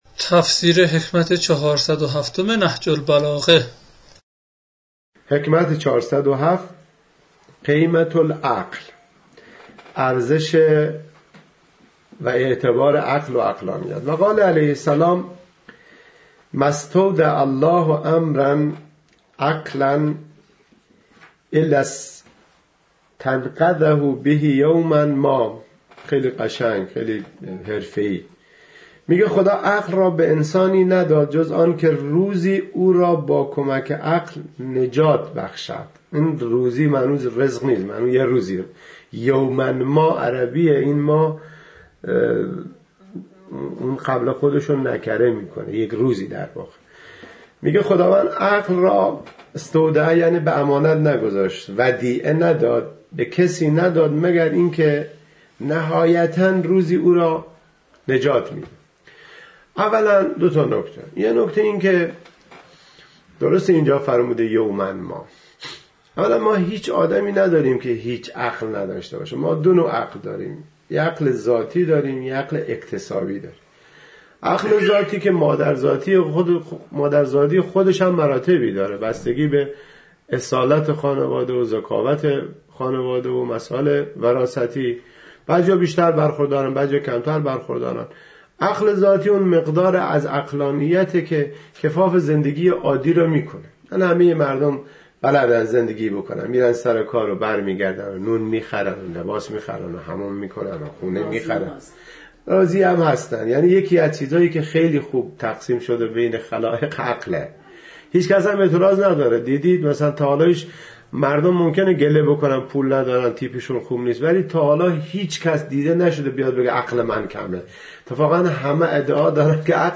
تفسیر صوتی حکمت 407 نهج البلاغه